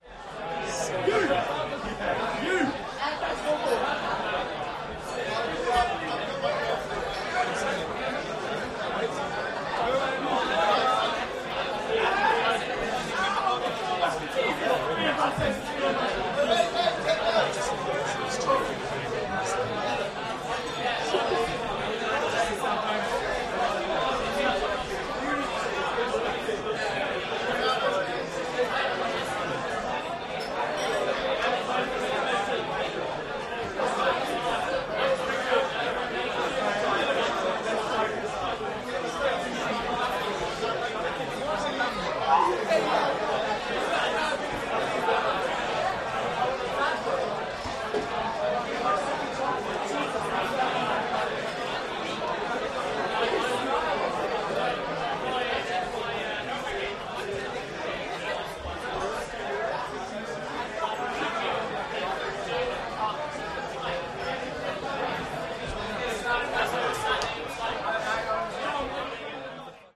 Bar ambience English | Sneak On The Lot
Pub bar club without music loud Walla crowd ambience